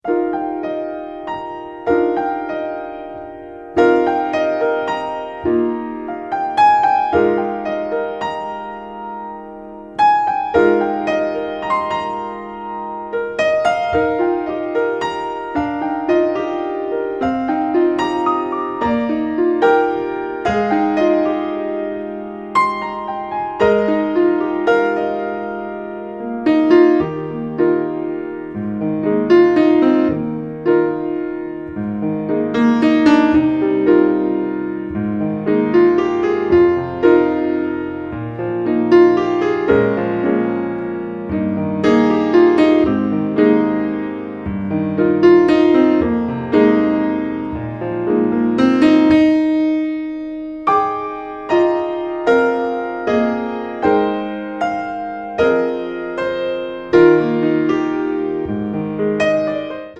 Voicing: "SATB, Descant, Cantor, Assembly"